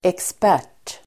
Uttal: [eksp'är_t:]